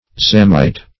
zamite - definition of zamite - synonyms, pronunciation, spelling from Free Dictionary Search Result for " zamite" : The Collaborative International Dictionary of English v.0.48: Zamite \Za"mite\, n. (Paleon.) A fossil cycad of the genus Zamia .